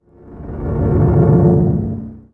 星际争霸音效-protoss-arbiter-pabfol01.wav